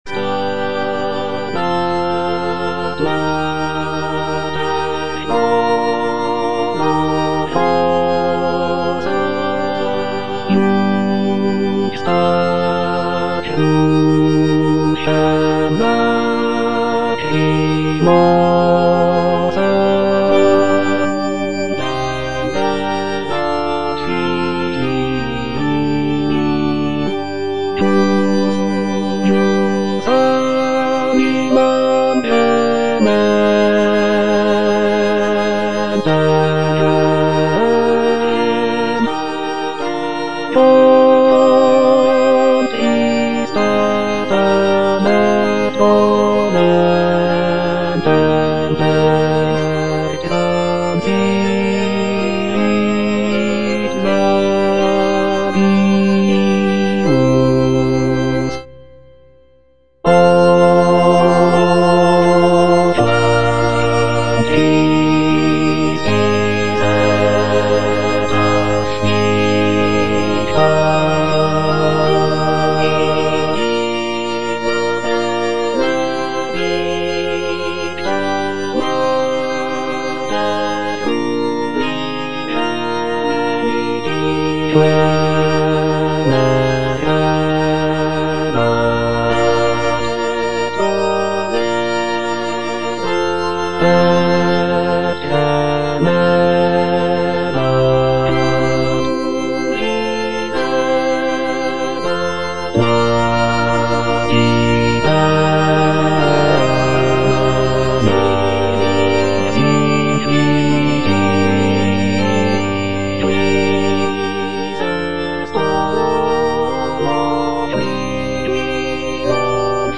G.P. DA PALESTRINA - STABAT MATER Stabat Mater dolorosa (bass II) (Emphasised voice and other voices) Ads stop: auto-stop
sacred choral work